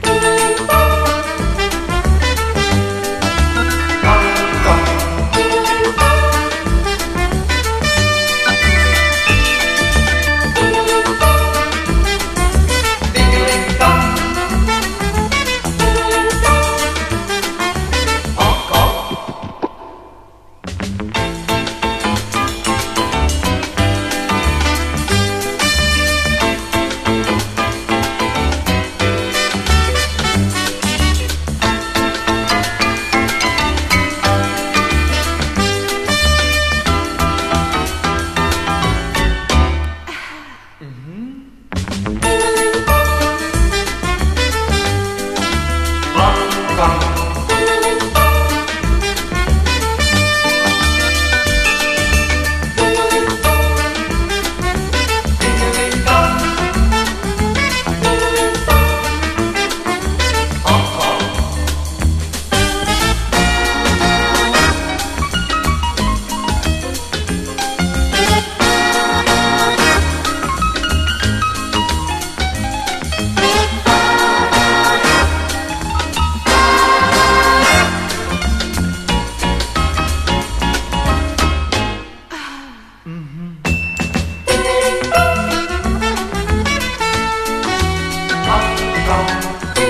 ROCK / 70'S (UK)
サックスを交えてドカドカと突き進む